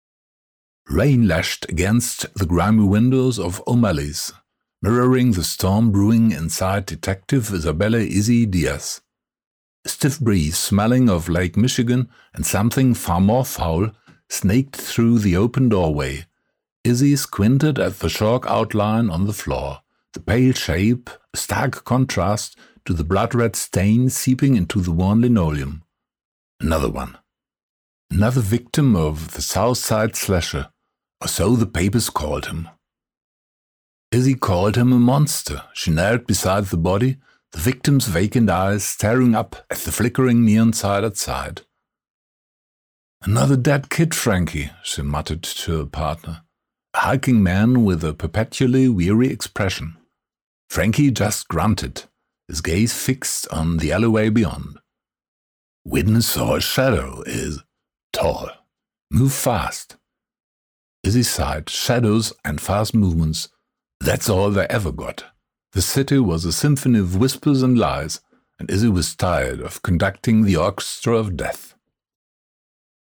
Demo AI-generated crime story.mp3